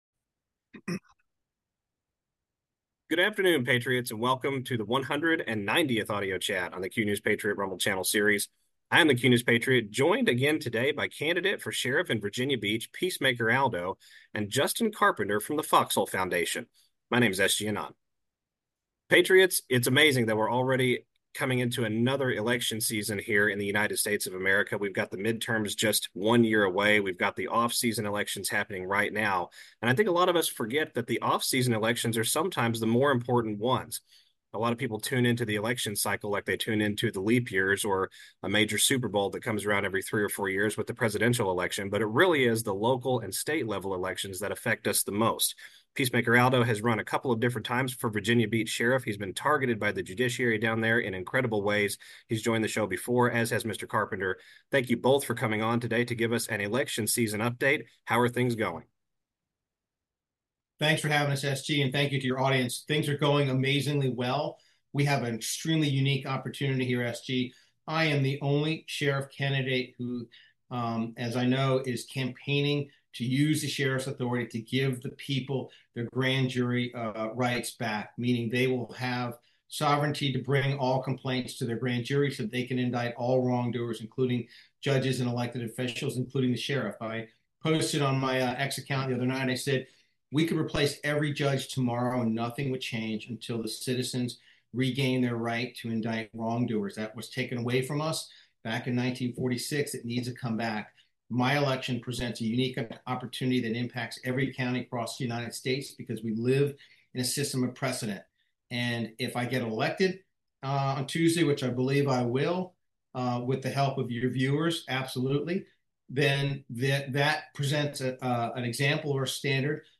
AUDIO CHAT 190